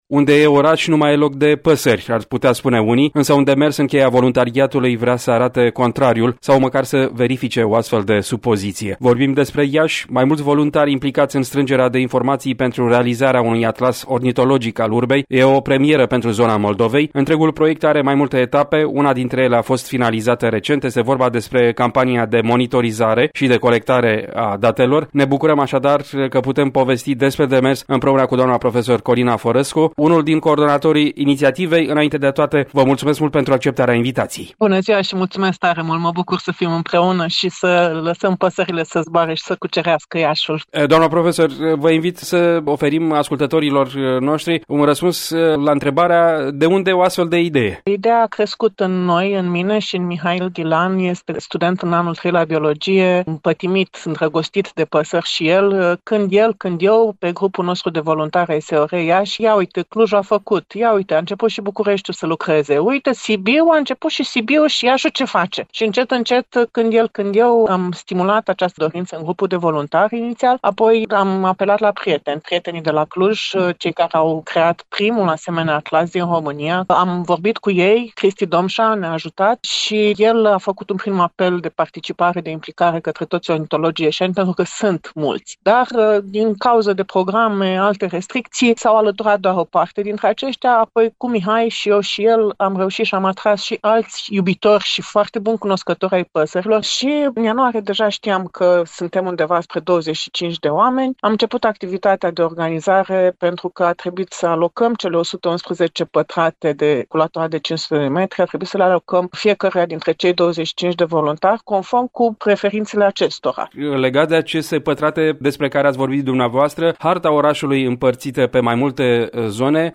Varianta audio a interviului: